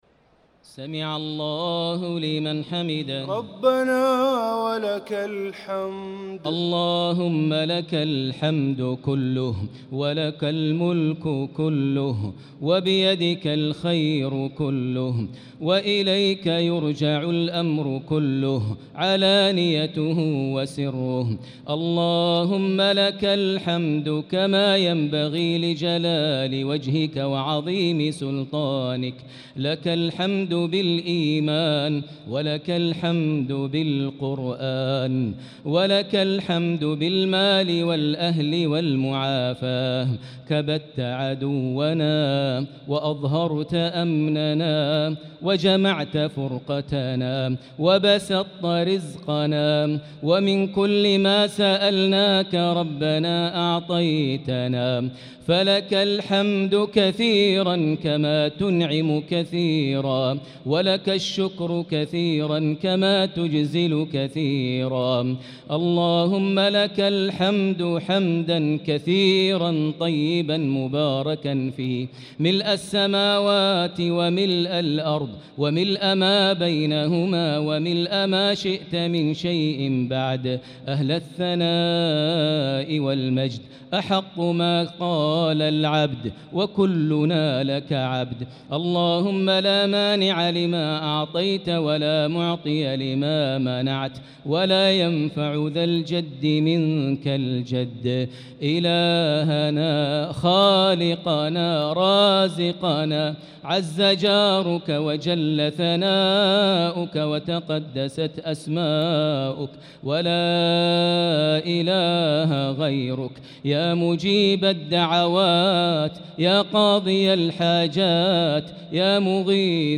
صلاة التراويح ليلة 26 رمضان 1445 للقارئ ماهر المعيقلي - دعاء القنوت